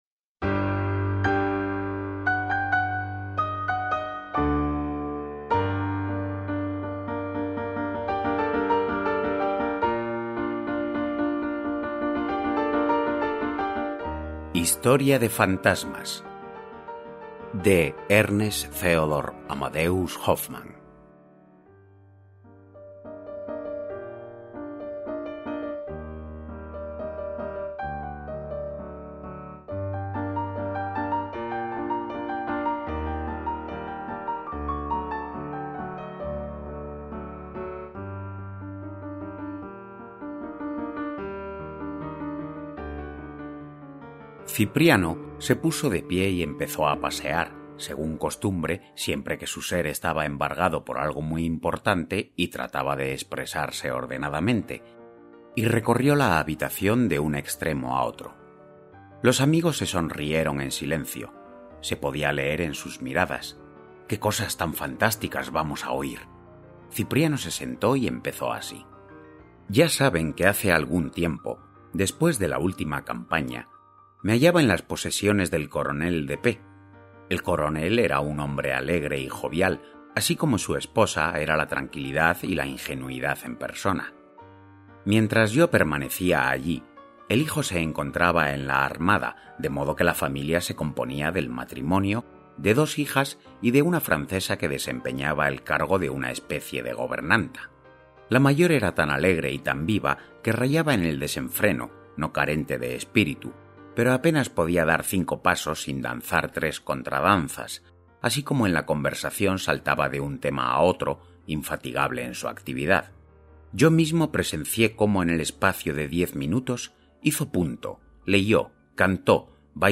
Audilibro: Historia de Fantasmas
Música: Ehma, Doc and Olga Scotland (cc:by-sa)
Audiolibros de Terror